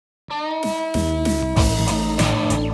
Cubase 11 Elements: Knack-Geräusch beim Exportieren
Beim Exportieren von einem Cubase-Projekt (Cubase 11 Elements) höre ich im exportierten WAV/MP3 File am Anfang ein knacksendes Geräusch und ich bekomme es einfach nicht weg.
Im MP3 im Anhang hört man das Knacksen bei 0,5.